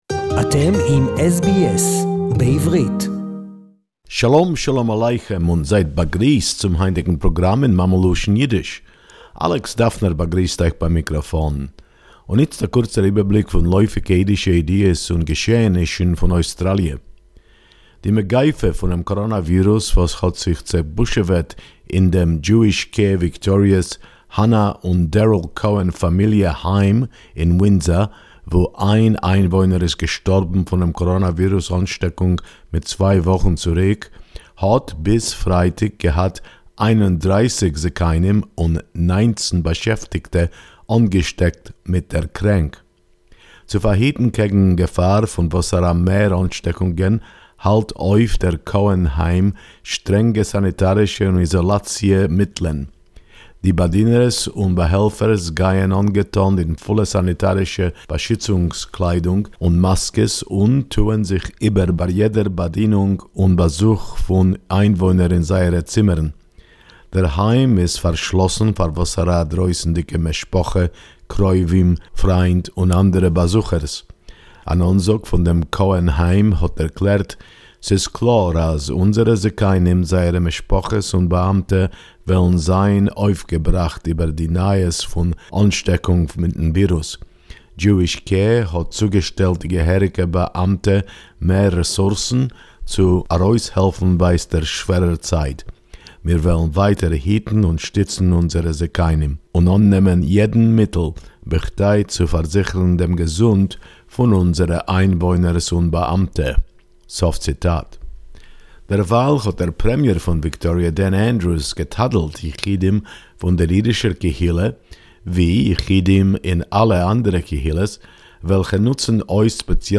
Melbourne Jewish Home for the elderly continued to suffer fr the impact of Covid-19 infections Yiddish report 23.8.2020